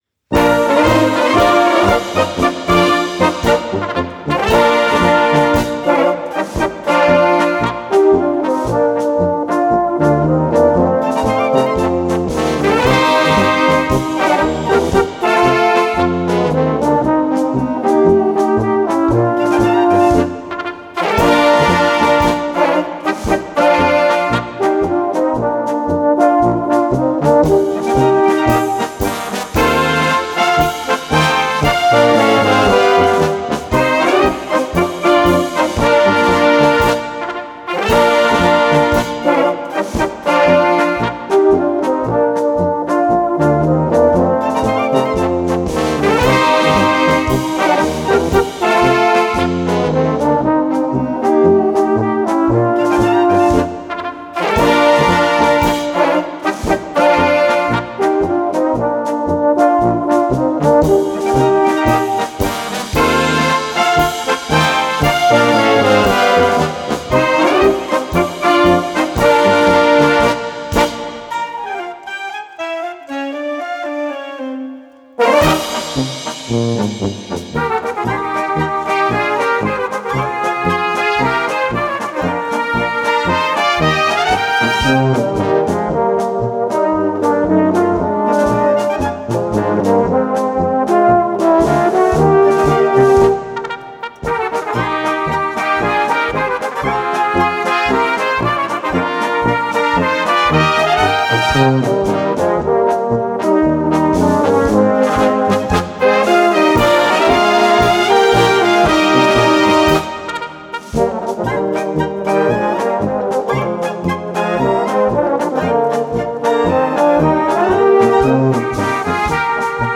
Polka´s für Blasmusik